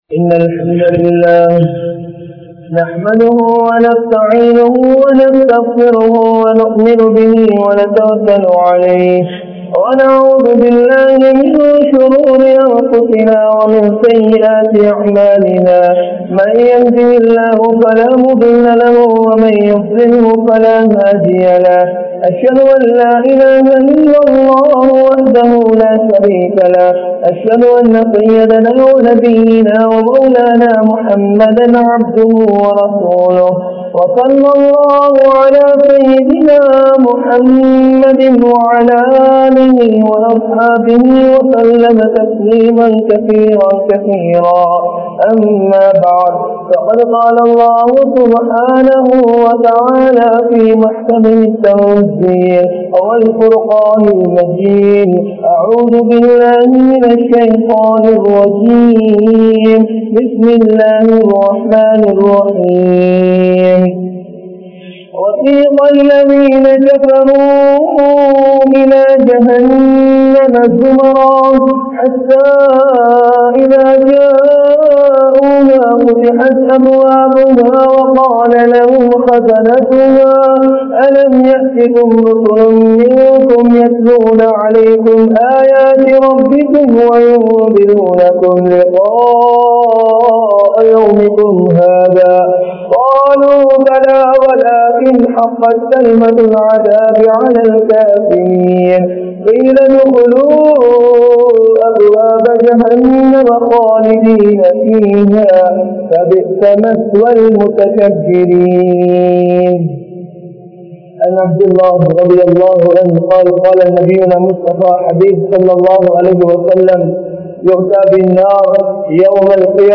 Al Quran Koorum Naraham (அல்குர்ஆன் கூறும் நரகம்) | Audio Bayans | All Ceylon Muslim Youth Community | Addalaichenai
Colombo 12, Aluthkade, Muhiyadeen Jumua Masjidh